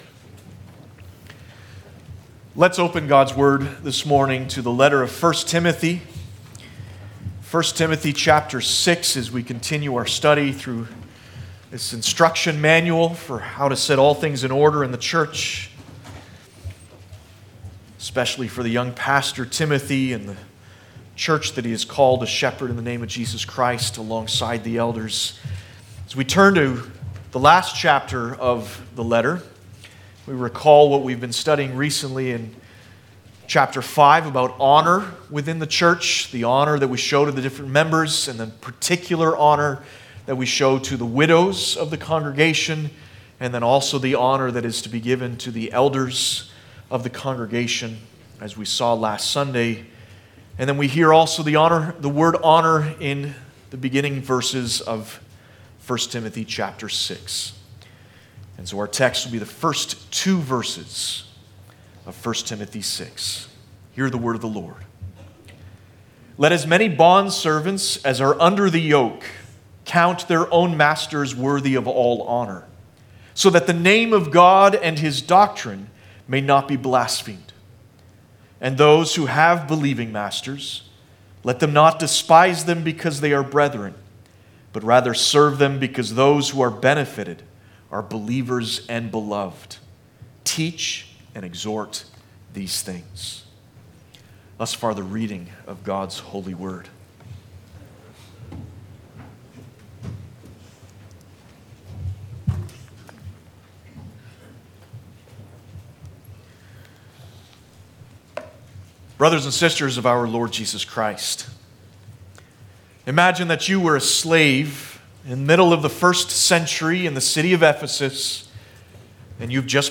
Passage: 1 Timothy 6: 1-2 Service Type: Sunday Morning